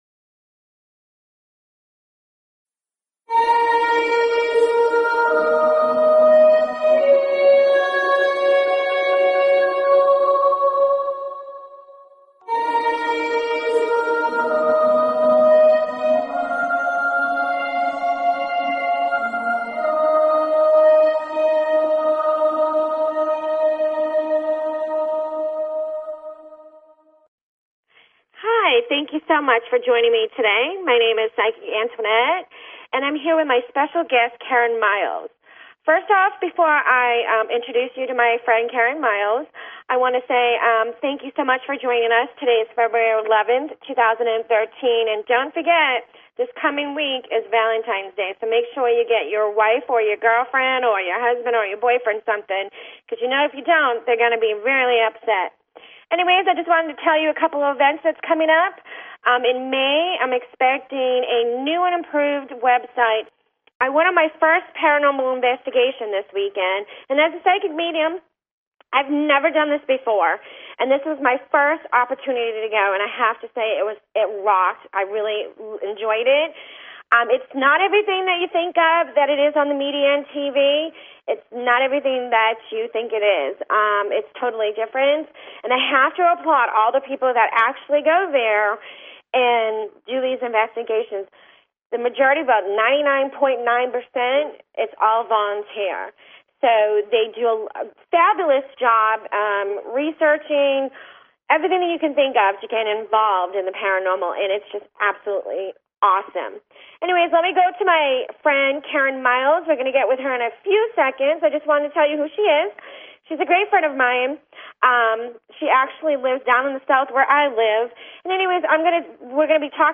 Talk Show Episode, Audio Podcast, Enlightenment_Hour and Courtesy of BBS Radio on , show guests , about , categorized as